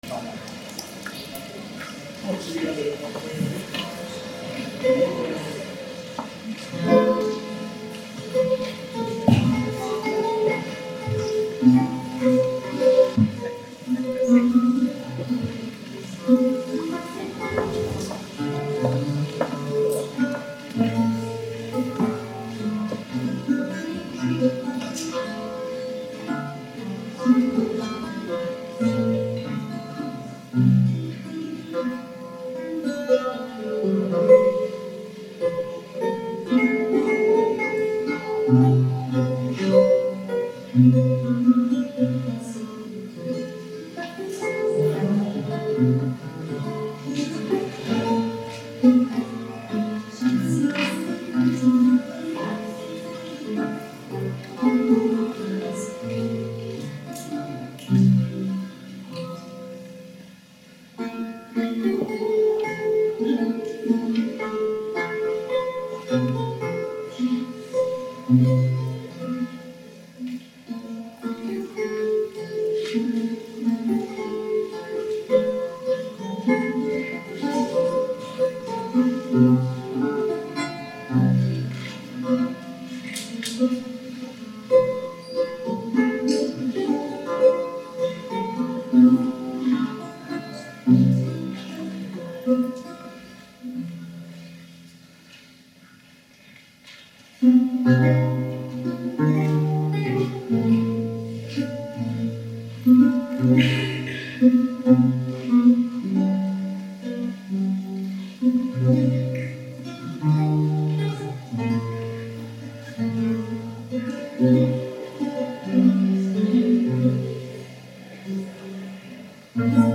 Történelem Tanszék alapításának 50 éves jubileuma - Megnyitó | VIDEOTORIUM
Rendezvény a szombathelyi történelem szakos tanárképzés indulásának és a Történelem Tanszék alapításának 50 éves jubileuma alkalmából.
Előadások, konferenciák